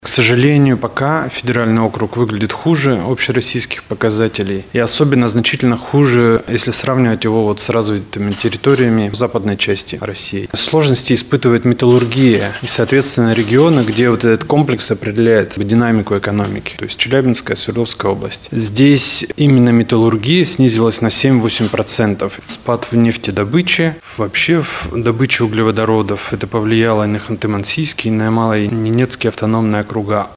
на пресс-конференции «ТАСС-Урал»